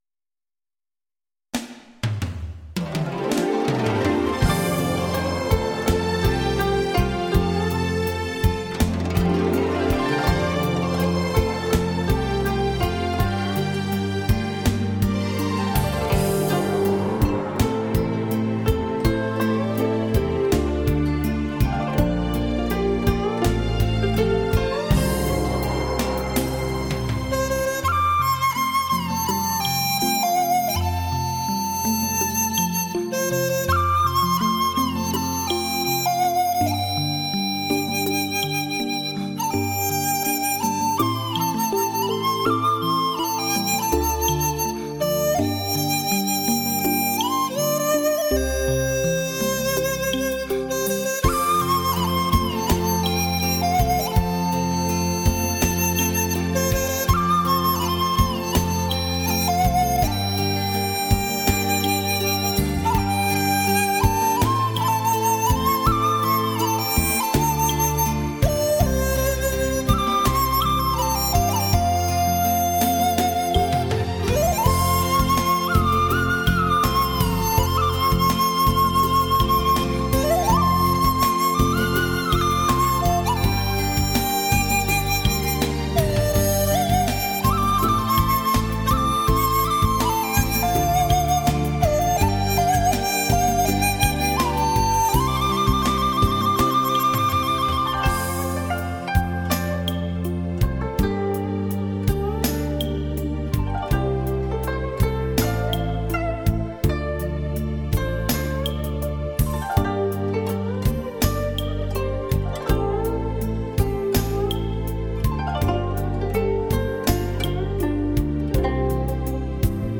笛子演奏
笛声时而高亢明亮，如江中流水。
清新的田园，悠扬的笛声！